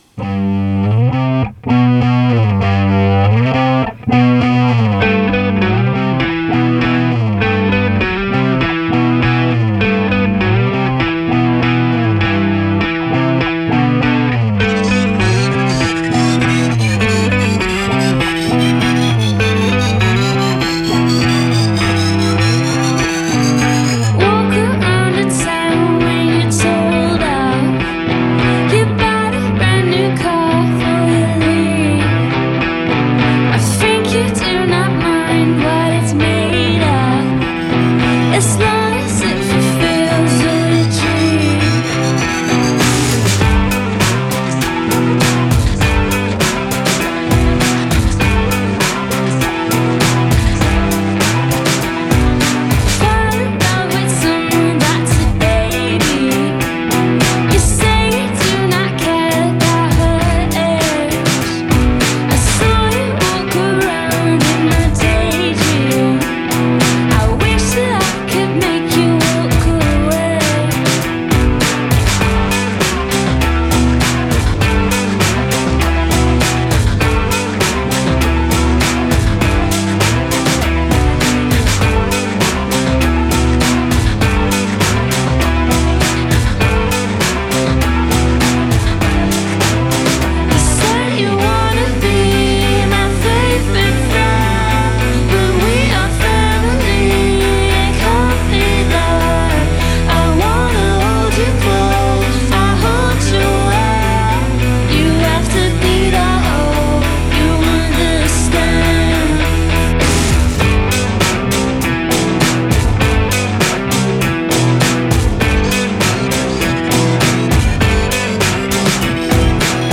recorded October 24, 2023
indie rock
thumping, carefree synth pop